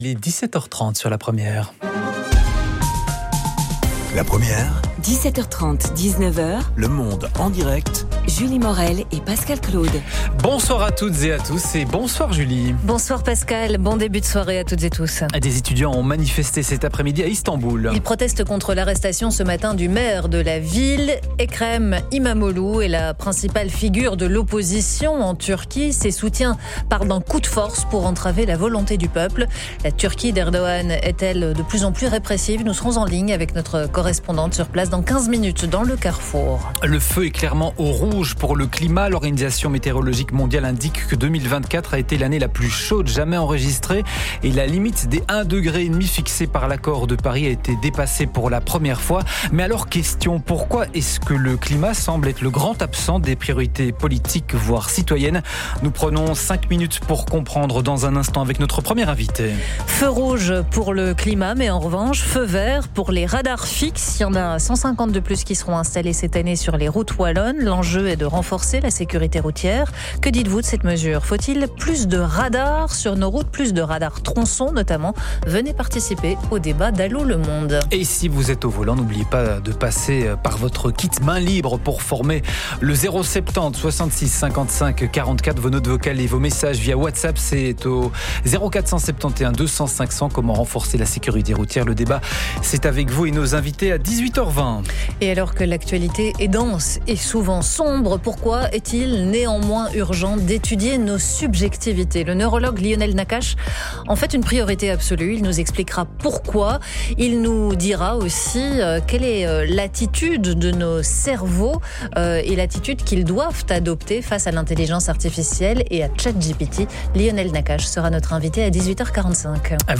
Reportage dans l’émission “Le Monde en direct”